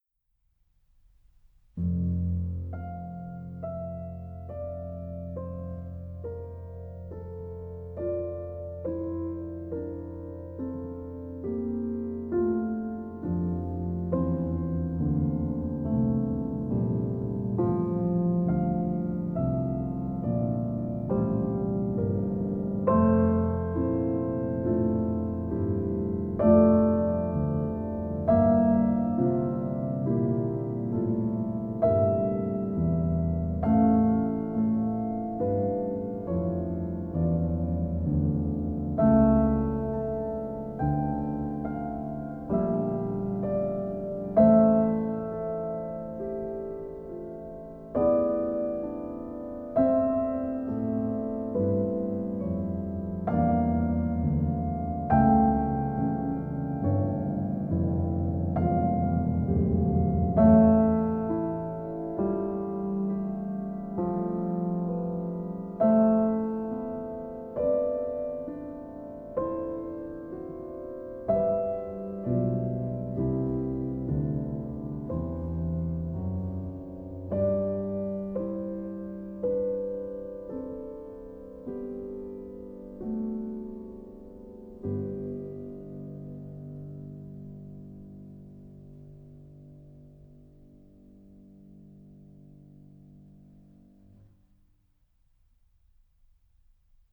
Classical, Contemporary, Romantic, Baroque, Folk